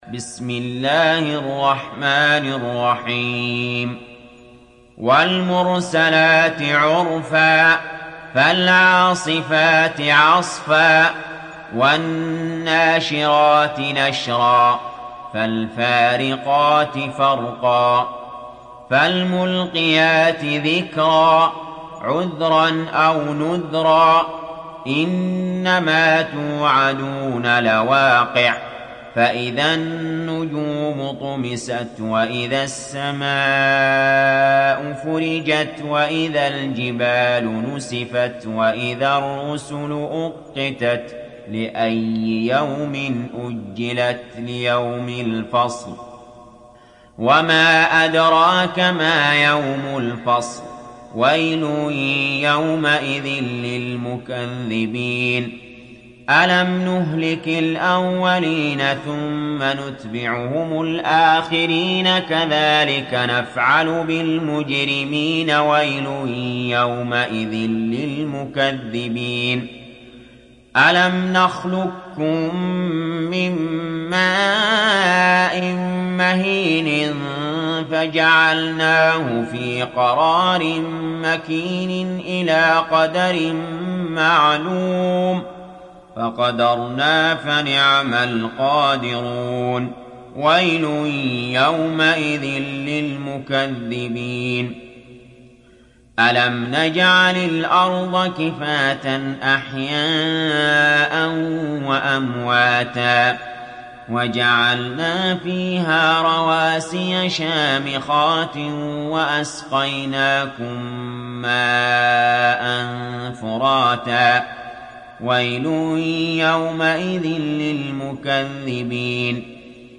دانلود سوره المرسلات mp3 علي جابر روایت حفص از عاصم, قرآن را دانلود کنید و گوش کن mp3 ، لینک مستقیم کامل